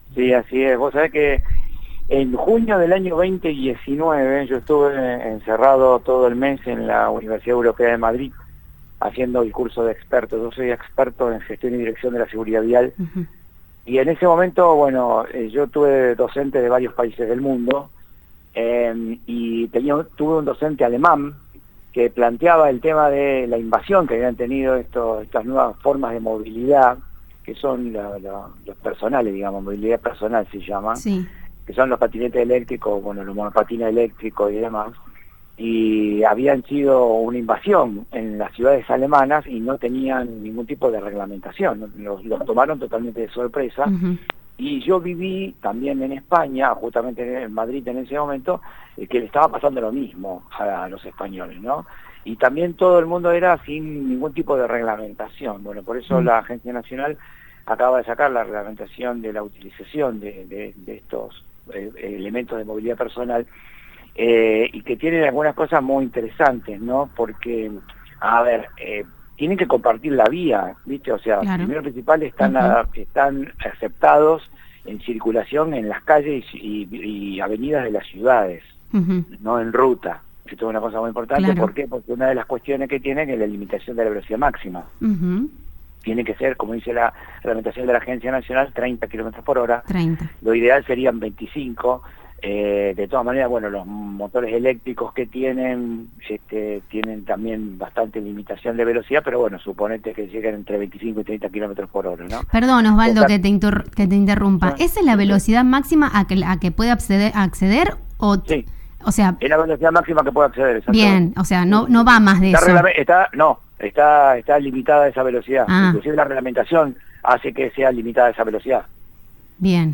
La Agencia Nacional de Seguridad Vial (ANSV) reglamentó el uso de monopatines y patinetas eléctricas y estableció que sólo pueden circular por las ciudades en calles y avenidas, la velocidad máxima es 30 km/h y la utilización del casco es obligatoria. En diálogo con el programa radial La Tarde Juntos de Radio Del Sur FM 107.3, el subsecretario Seguridad Vial Santa Fe Osvaldo Aymo, se expresó al respecto.